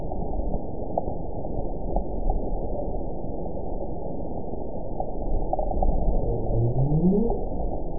event 910859 date 02/01/22 time 07:23:41 GMT (3 years, 3 months ago) score 8.45 location TSS-AB05 detected by nrw target species NRW annotations +NRW Spectrogram: Frequency (kHz) vs. Time (s) audio not available .wav